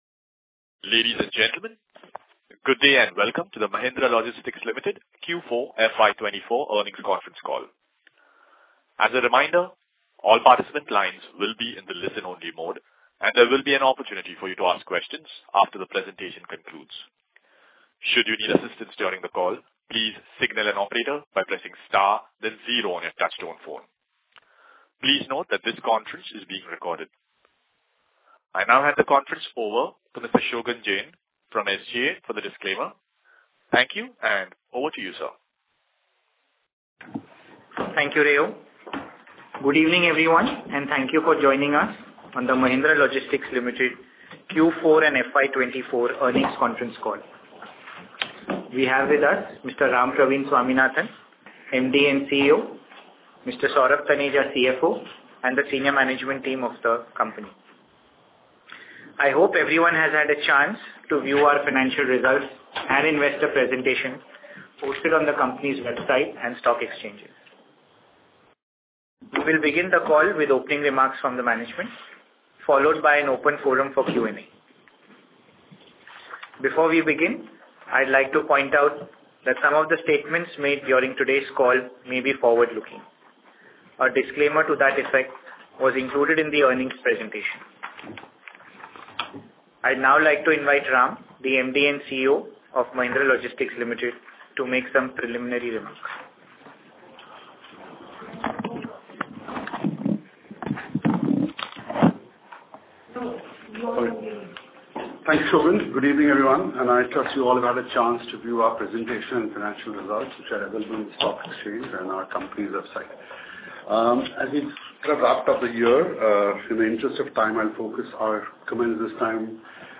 Audio Transcript Earnings Call – Q4FY24.mp3